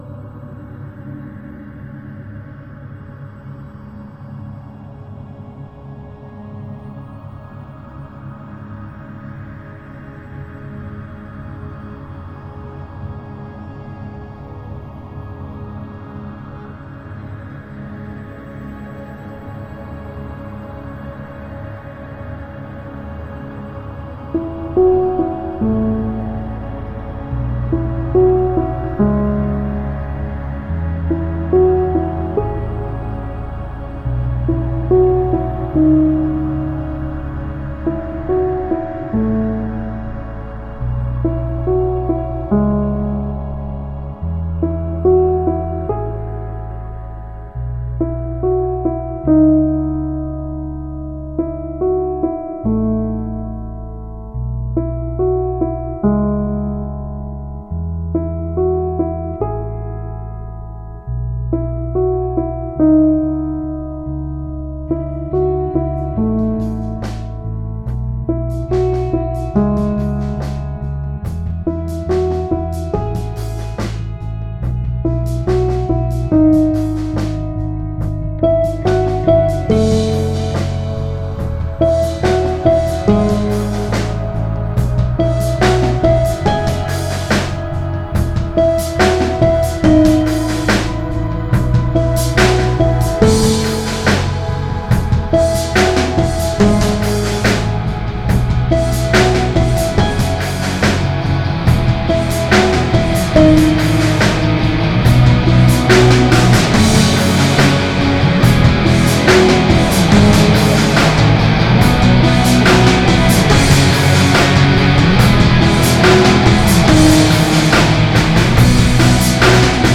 Very nice chill songs